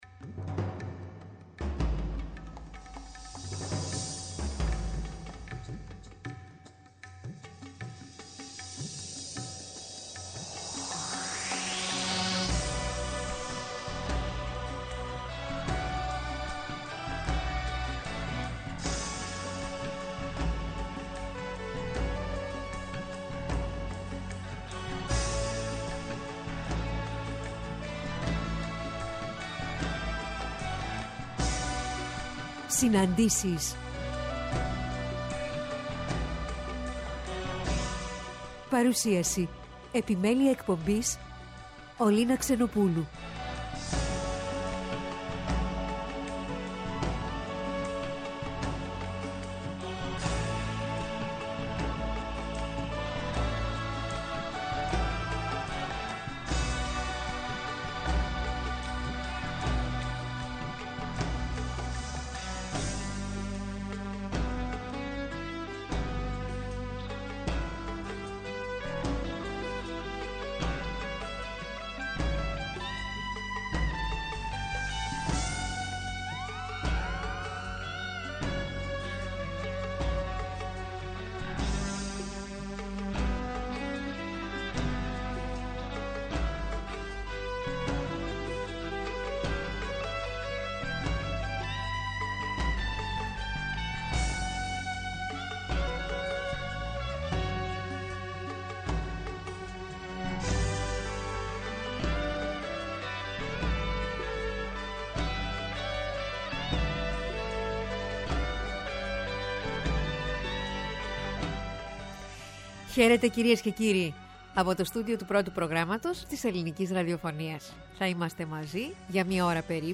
Στις ΣΥΝΑΝΤΗΣΕΙΣ καλεσμένη σήμερα η Συγγραφέας, Ελένη Λαδιά.